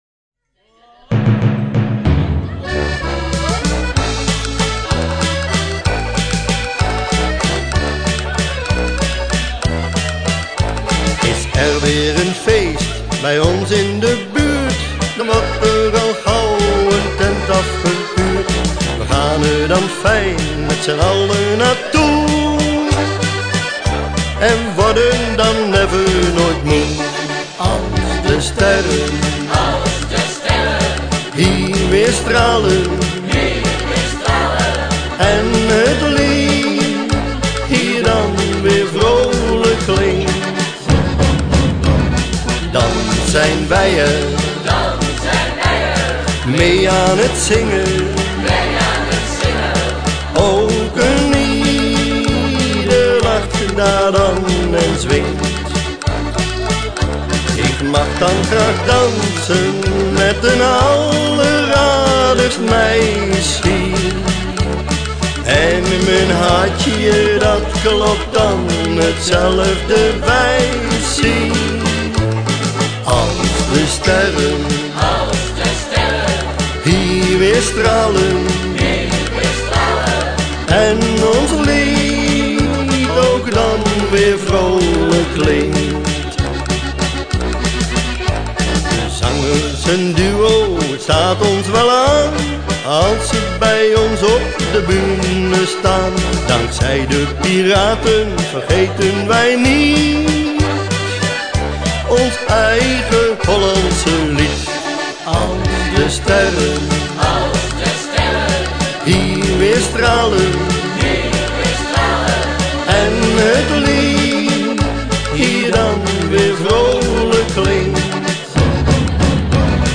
zijn zelfgeschreven lied op te nemen en uit te brengen.